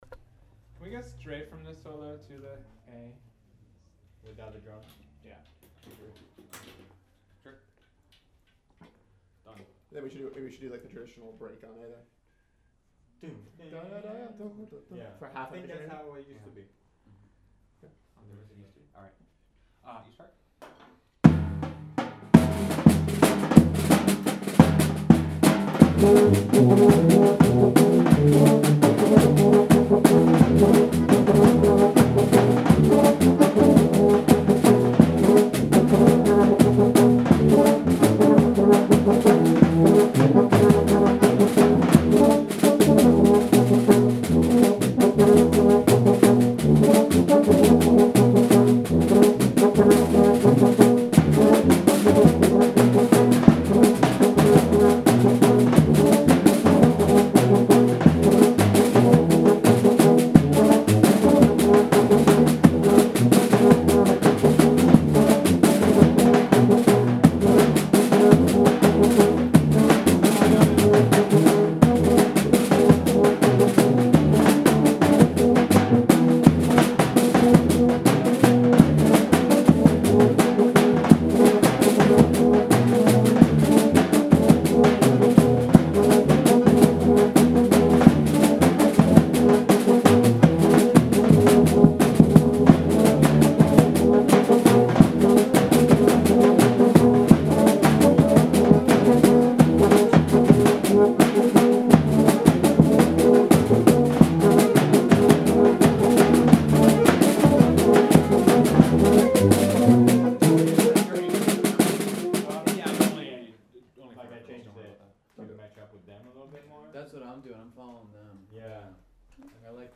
fatalno_chuslo-mids-and-drums_2-4-14_rehearsal.mp3